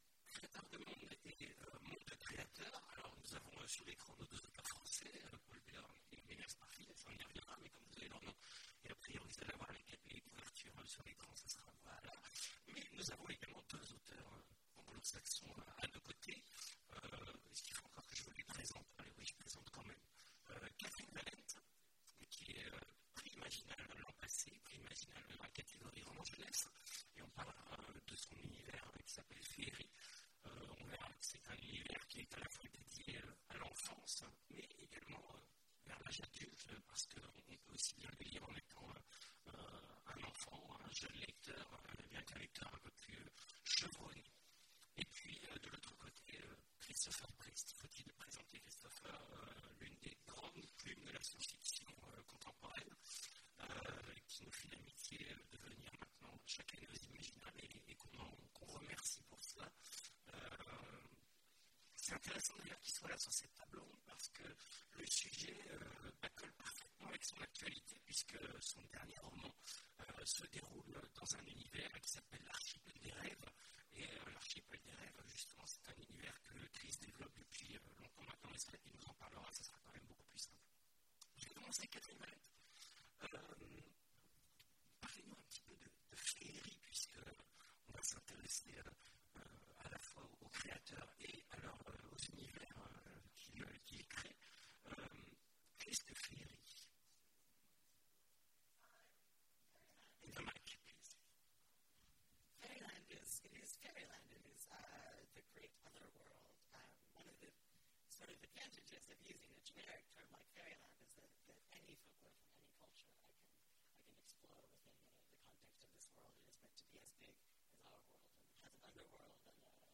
Imaginales 2017 : Conférence Créateurs de mondes... monde de créateurs !